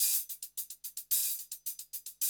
HIHAT LOP3.wav